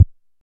SubImpactSweetener FS048901
Sub Impact Sweeteners; Short And Low Thud Sweetener. - Fight Sweetener